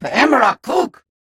Unggoy voice clip from Halo: Reach.